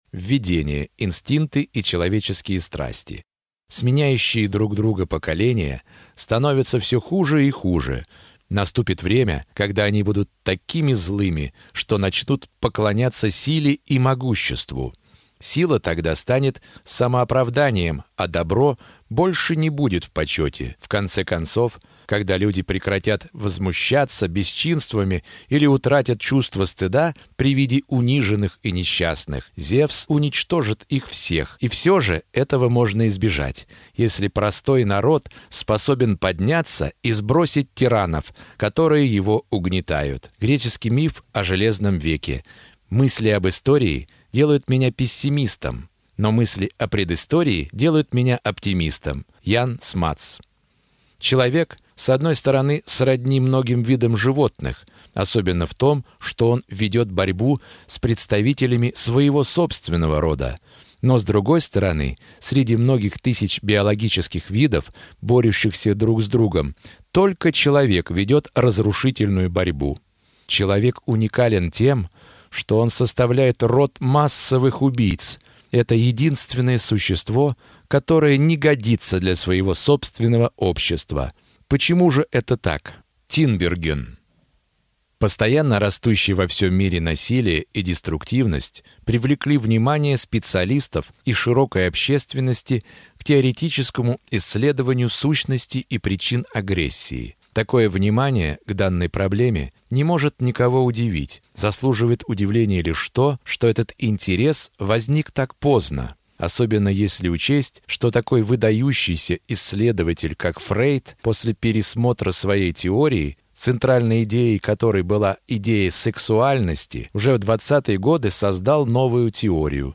Аудиокнига Анатомия человеческой деструктивности | Библиотека аудиокниг